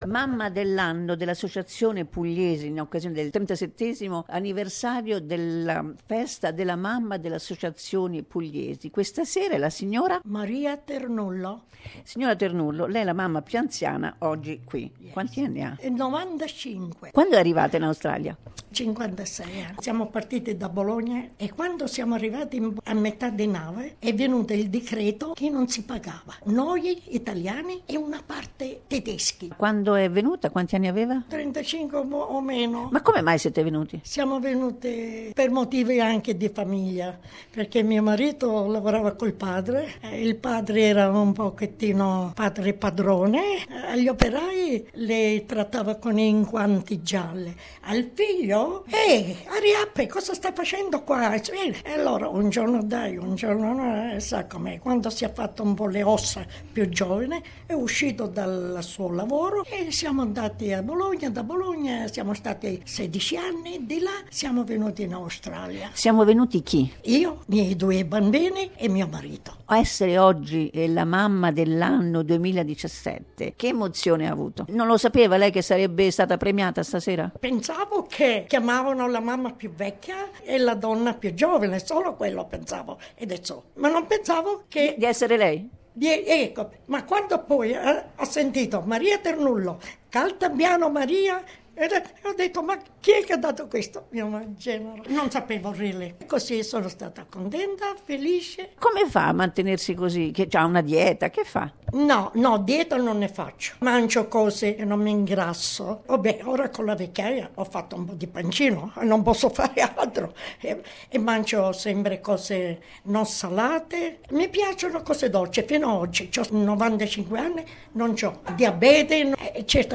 Report and interviews recorded during Mother's Day celebrations 2017 at the NSW Apulian Association.